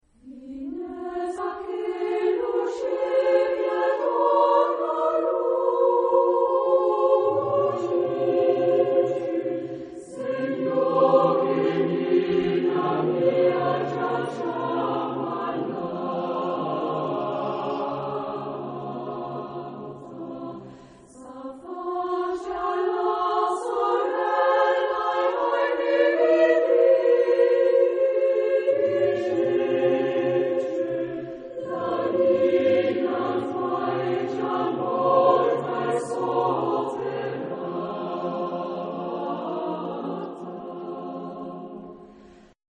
Genre-Style-Forme : Populaire ; Profane
Caractère de la pièce : triste
Type de choeur : SATB  (4 voix mixtes )
Tonalité : fa mineur